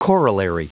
Prononciation du mot corollary en anglais (fichier audio)
Prononciation du mot : corollary